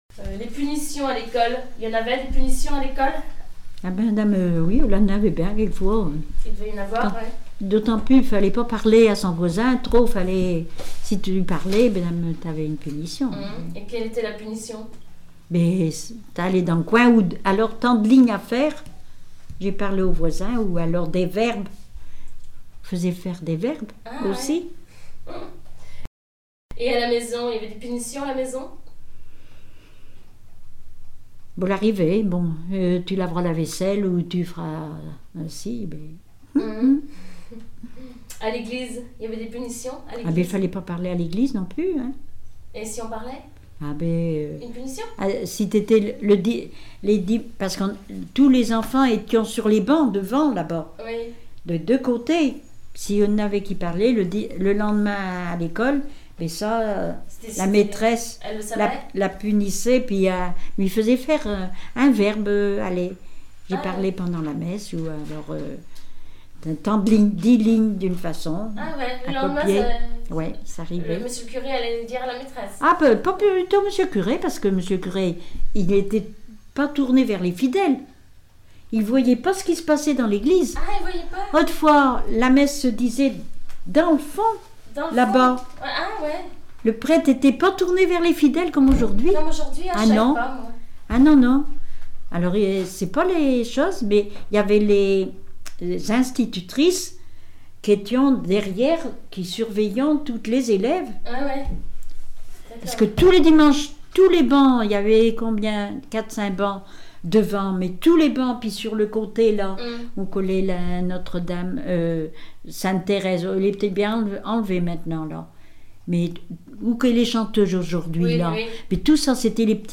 Témoignages sur la vie domestique